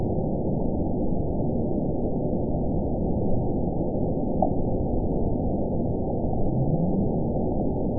event 917807 date 04/17/23 time 01:00:56 GMT (2 years, 1 month ago) score 9.35 location TSS-AB03 detected by nrw target species NRW annotations +NRW Spectrogram: Frequency (kHz) vs. Time (s) audio not available .wav